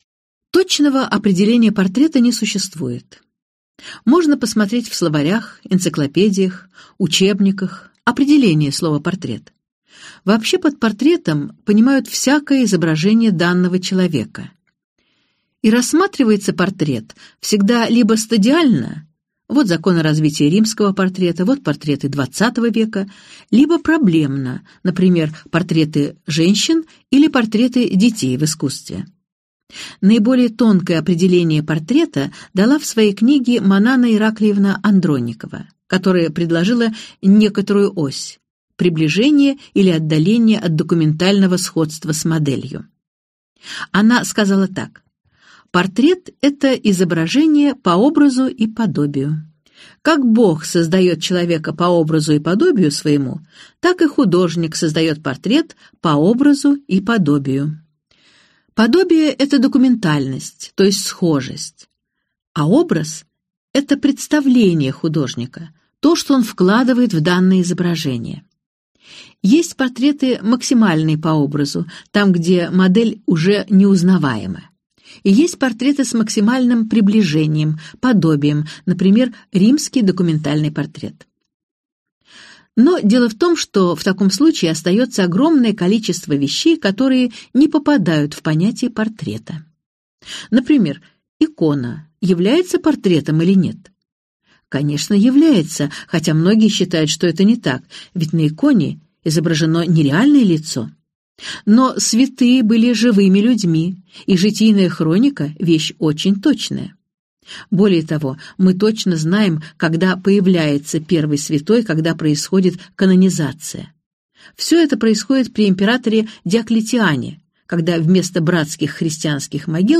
Аудиокнига Мост через бездну. Великие мастера | Библиотека аудиокниг